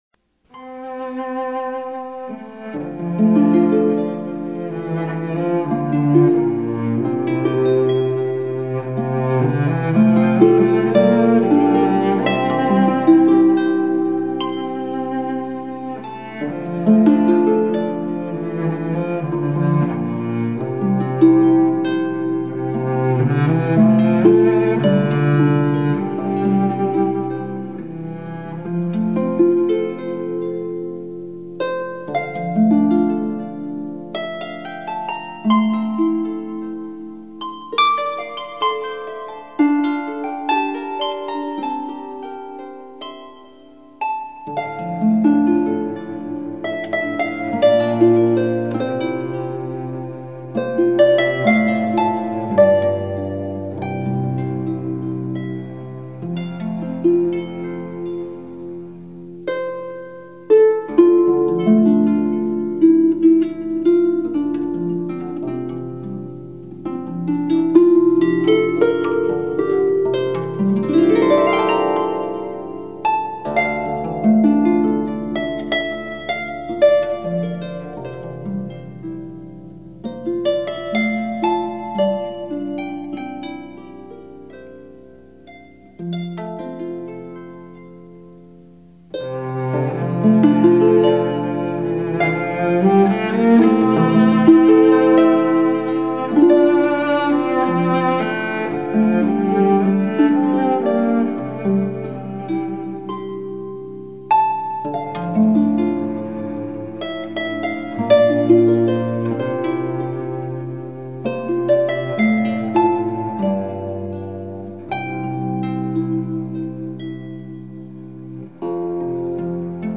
音乐类别：爵士人声
一切的一切，目的就是为了表现凯尔特音乐特有的旋律和味道。
音乐的旋律固然极其古朴而悠扬，但是歌词听来都具有爱尔兰特有的韵味。